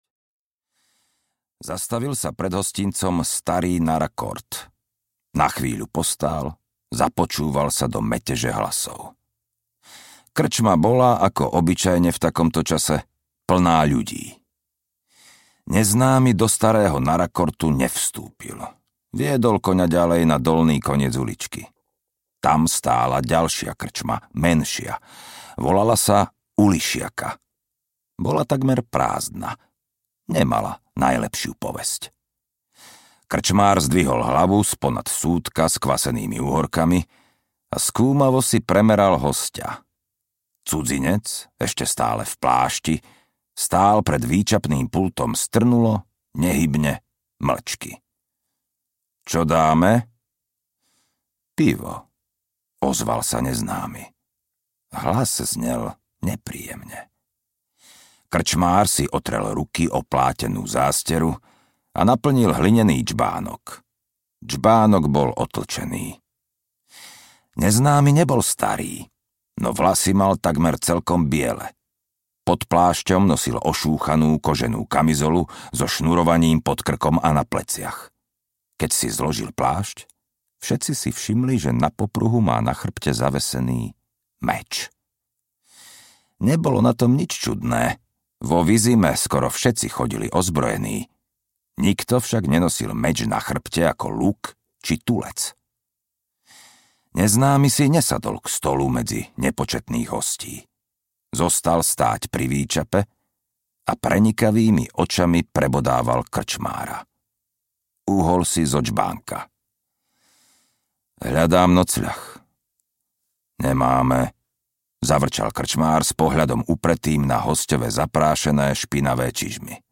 Zaklínač I: Posledné želanie audiokniha
Ukázka z knihy